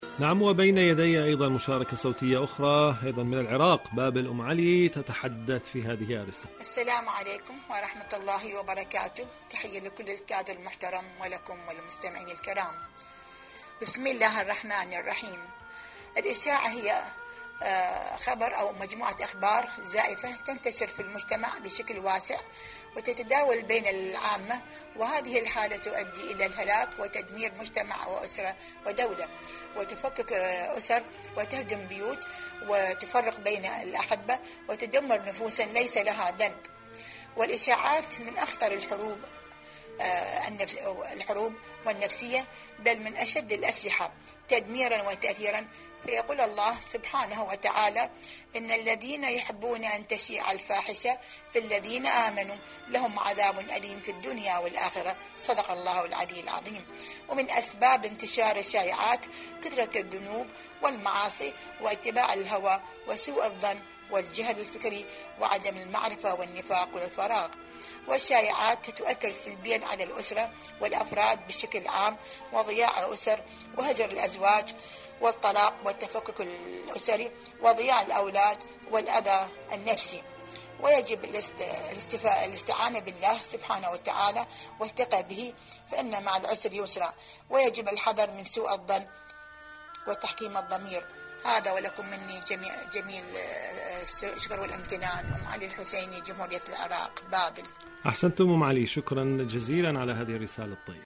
إذاعة طهران-معكم على الهواء: